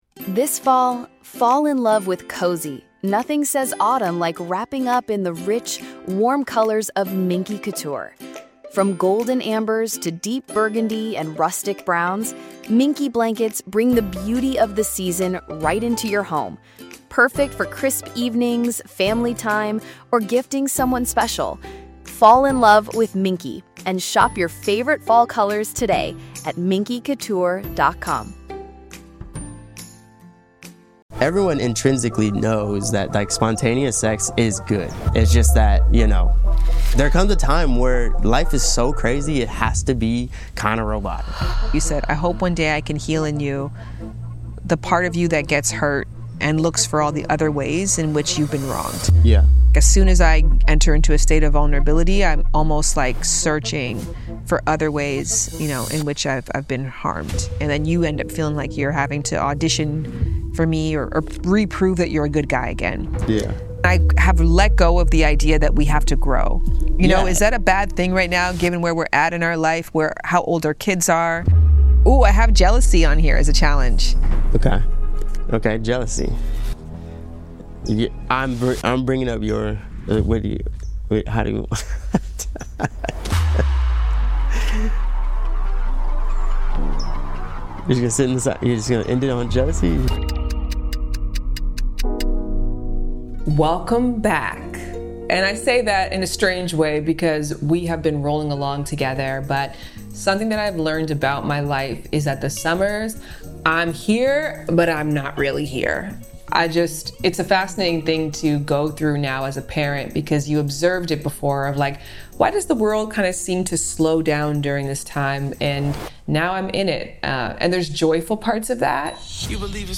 This raw and real conversation reveals how couples can stay aligned, navigate change, and keep intimacy thriving over time.